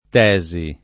tesi [ t $@ i ] s. f.